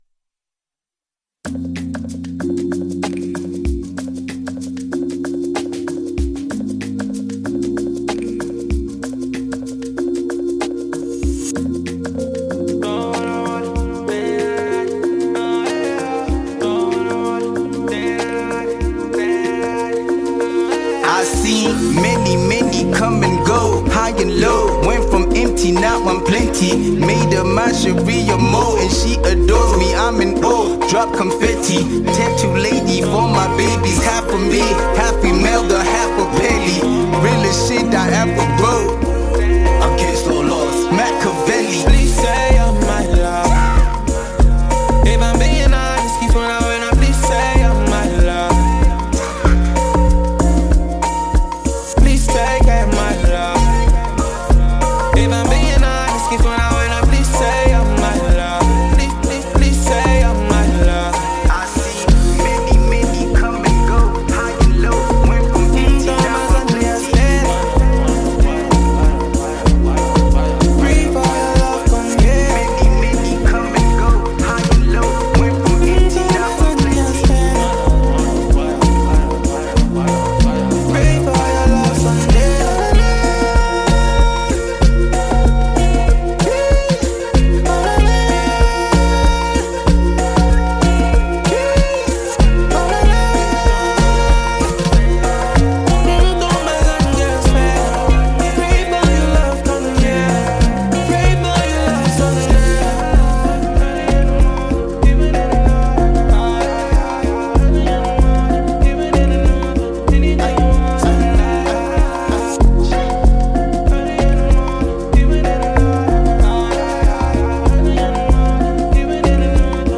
a rapper from South Africa